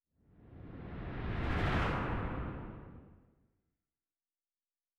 Distant Ship Pass By 6_1.wav